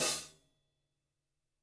014_mdk_hatclosed21.wav